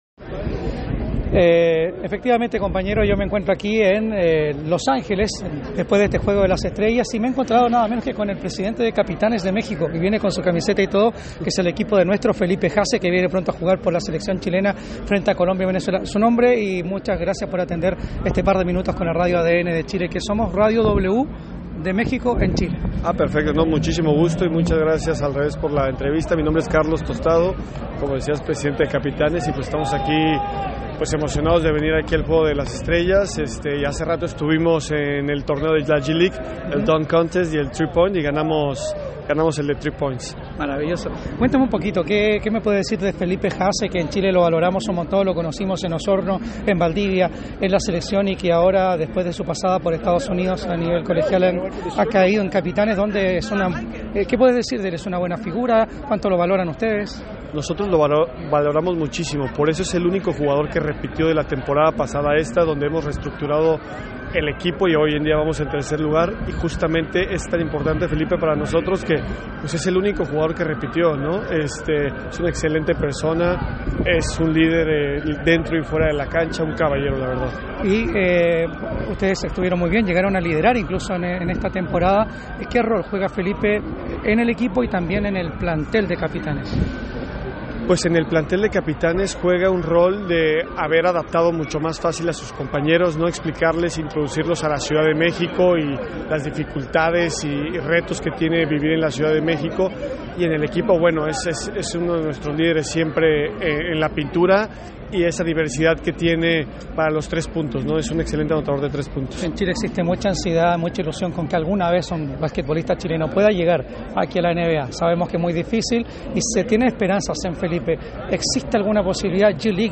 En diálogo con ADN Deportes